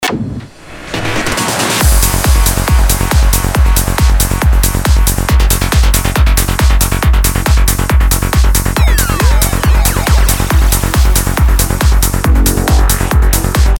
Нужен такой характерный Goa,Psy бас.
Здравствуйте, очень интересует как делать подобный psy трансовый бас.